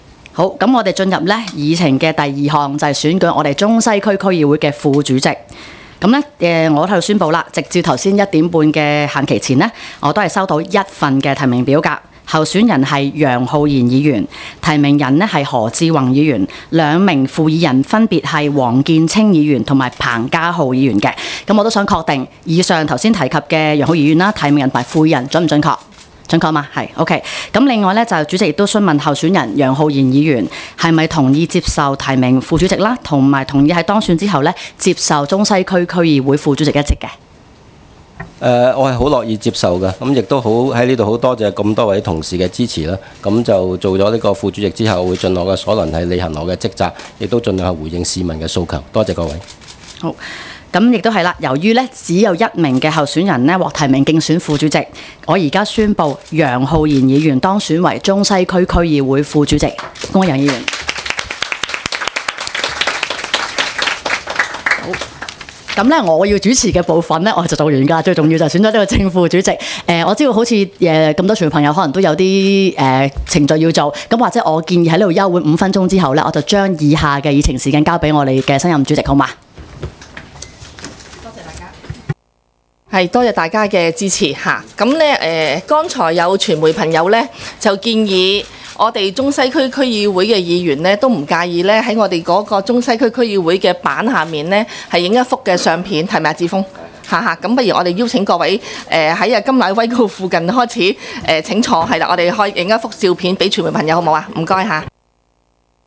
区议会大会的录音记录
中西区区议会会议室